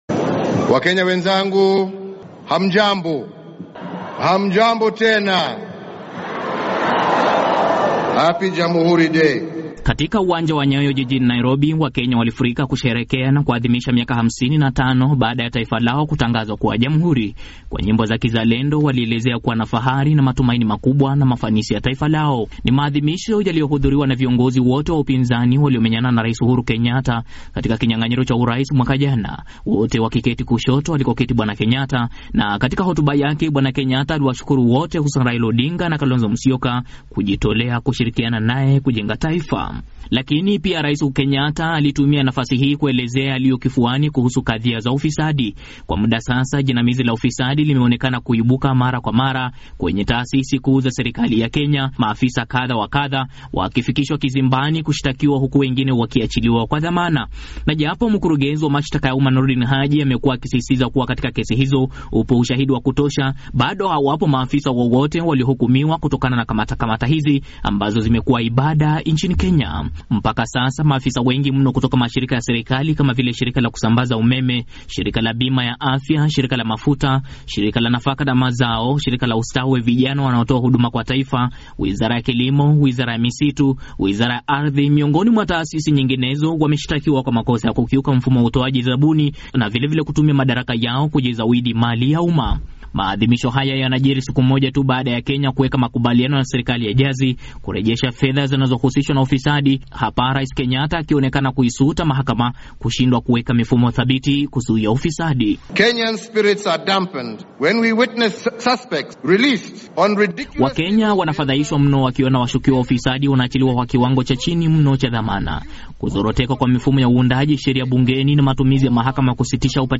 anasimulia ripoti hii kutoka Nairobi